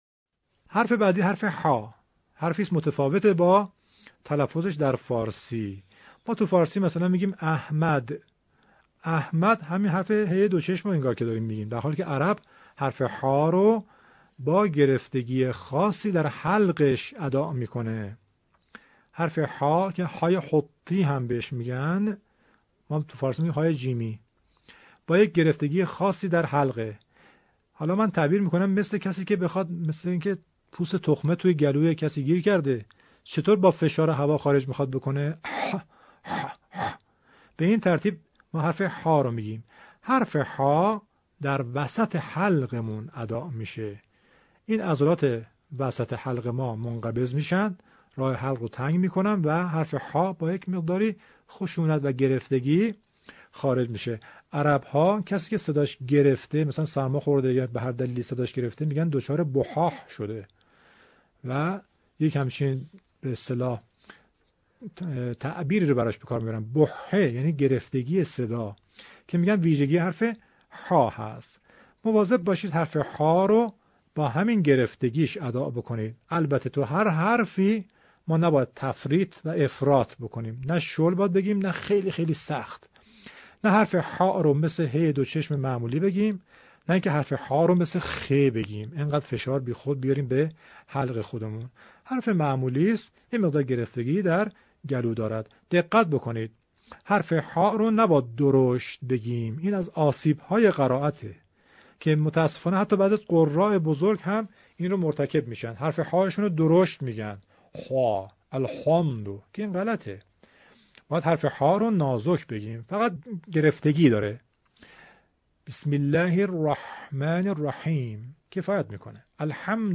💠تلفظ حرف «ح»💠